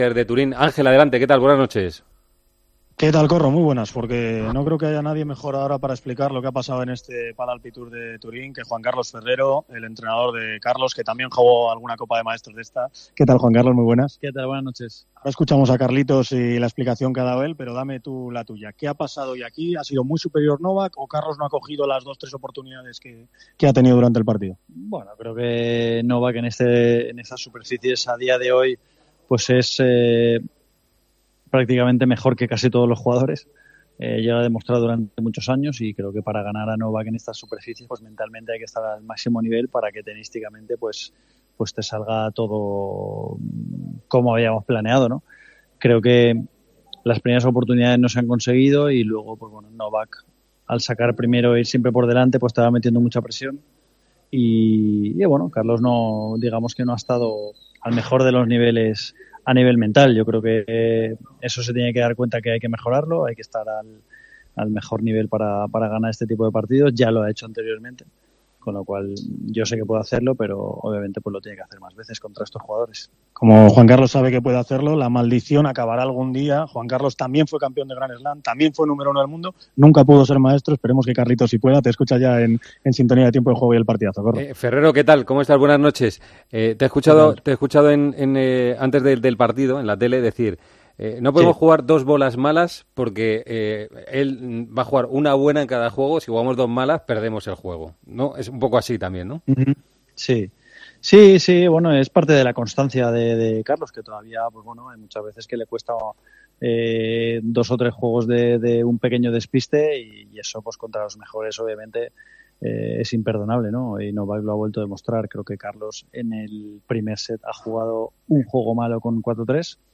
El entrenador del tenista murciano ha pasado por los micrófonos de Tiempo de Juego y ha analizado la semifinal de Carlos contra Djokovic y la globalidad de su temporada.